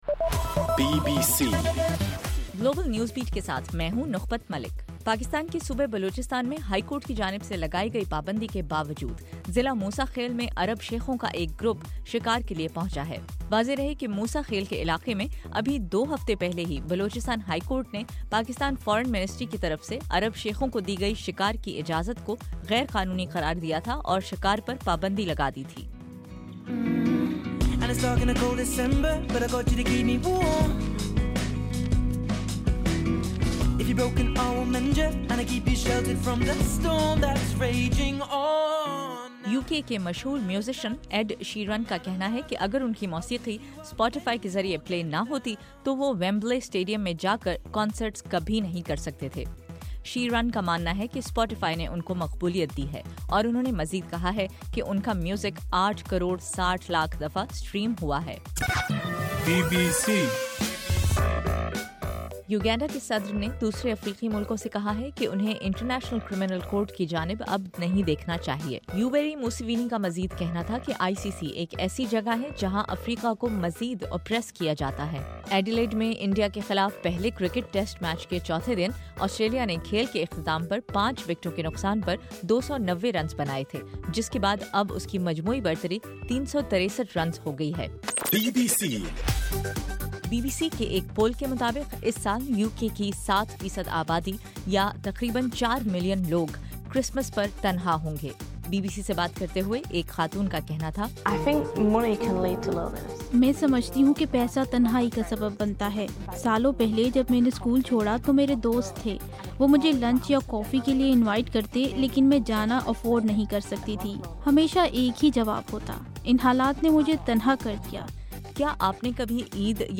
دسمبر 12: رات 12 بجے کا گلوبل نیوز بیٹ بُلیٹن